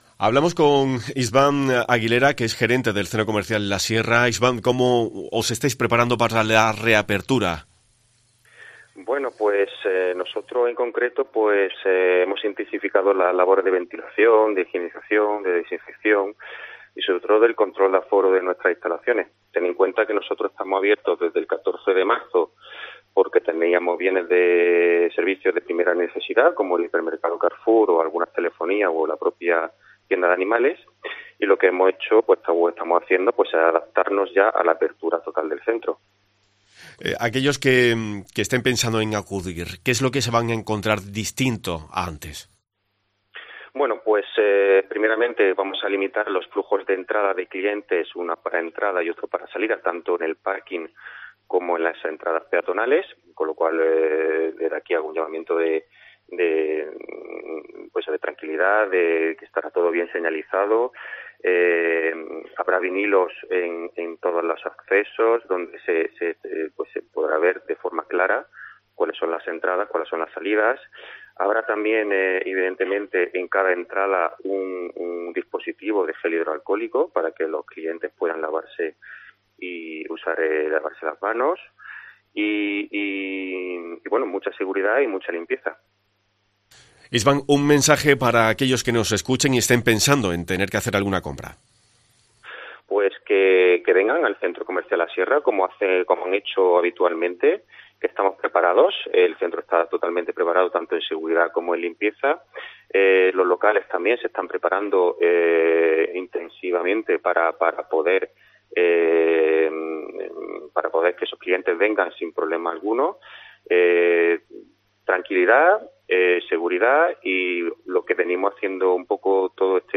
responde a las preguntas